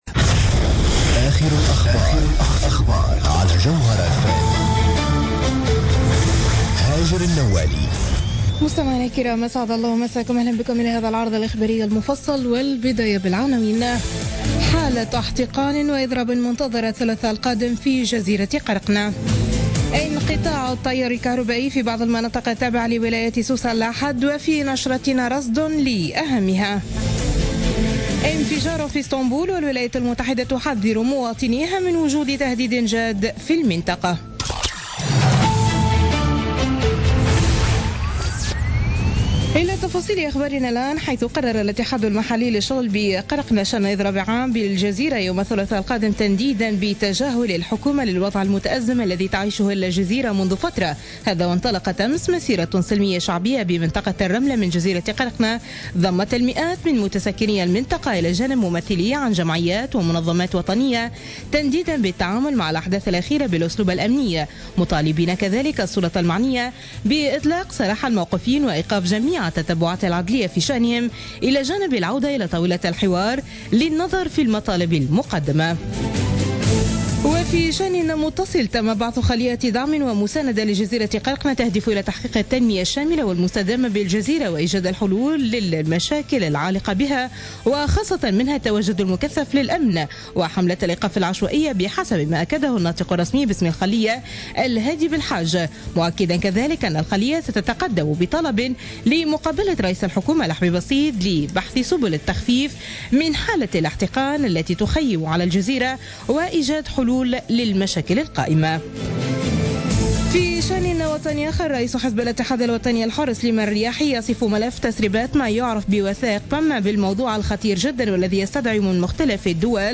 نشرة أخبار منتصف الليل ليوم الأحد 10 أفريل 2016